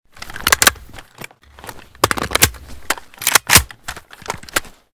saiga_reload_empty.ogg